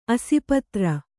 ♪ asipatra